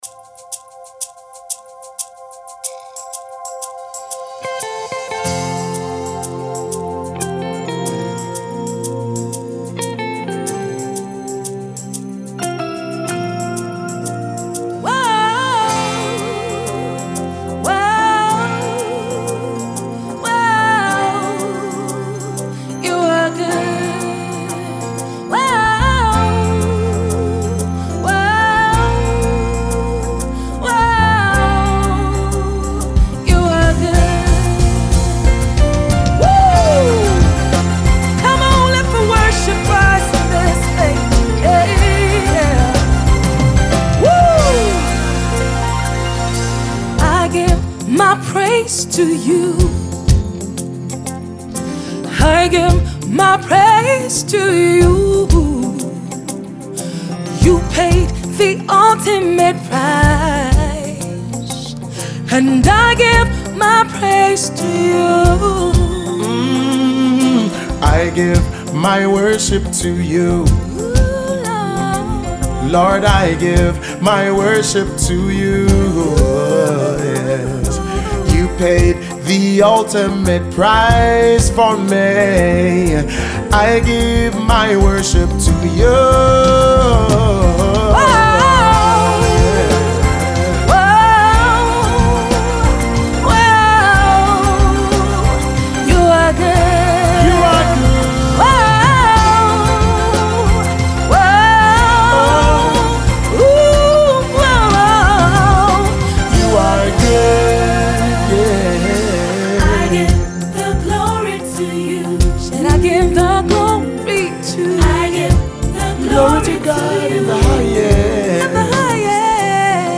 International Gospel music minister
Sharing the inspiration behind the soulful single